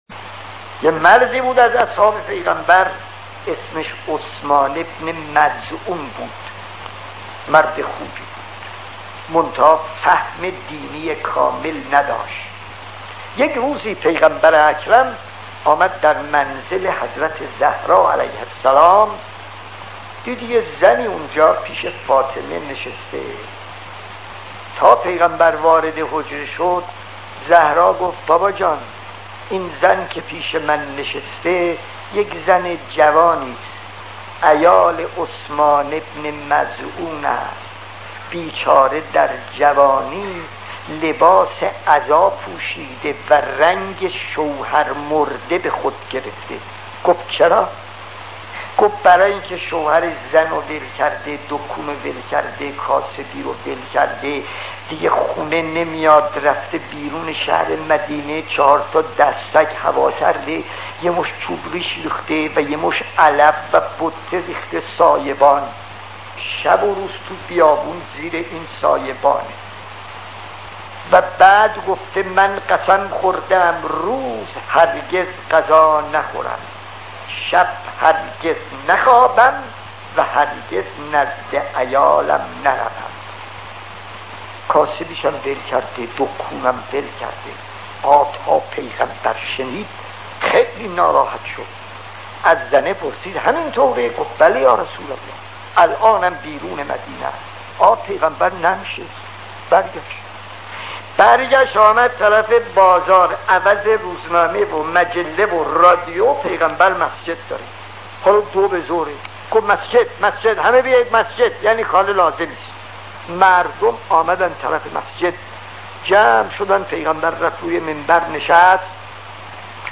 خطیب: استاد فلسفی مدت زمان: 00:05:31